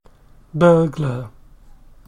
burglar /ˈbɜːɡlə/ are very useful to realize that /ə/ and /ɜː/ have the same vowel quality and only differ in stress and length.